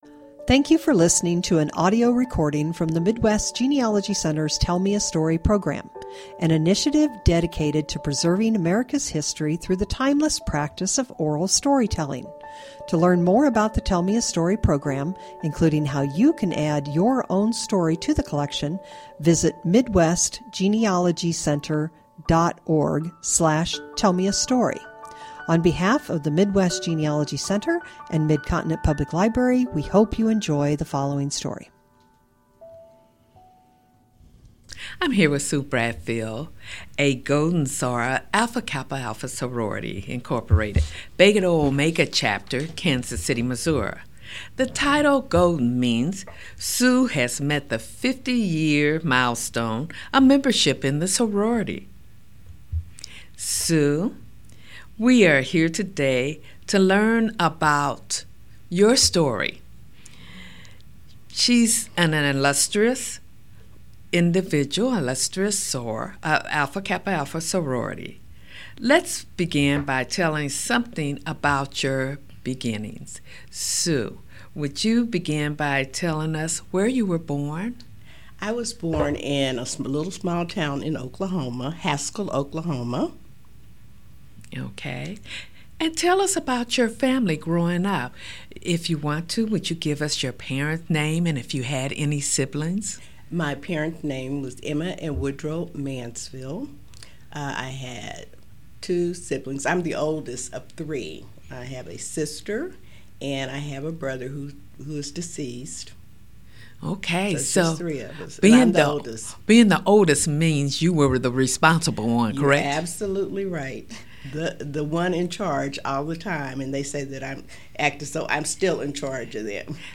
Genealogy Family history Oral history